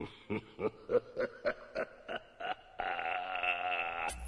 Nep_EvilLaugh.wav